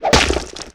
tentackle.wav